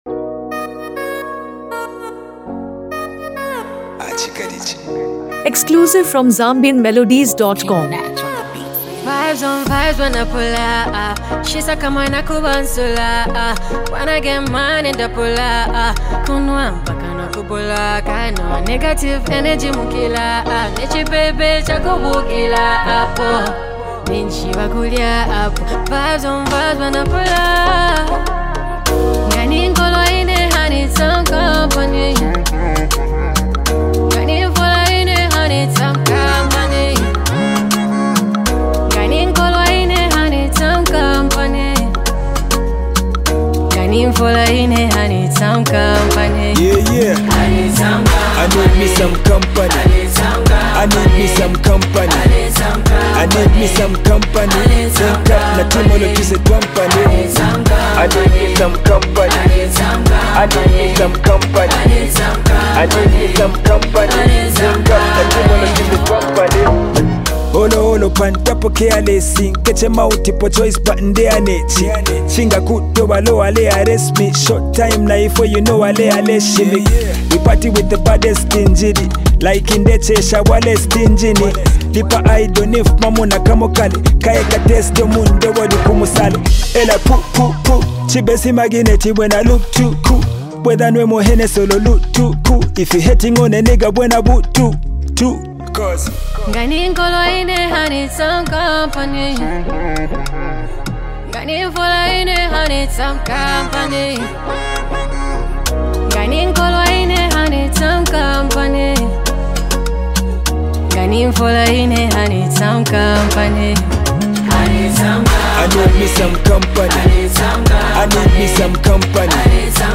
a high-energy Zambian track
a catchy Afro-fusion anthem
Blending Afrobeat, dancehall, and Zambian urban vibes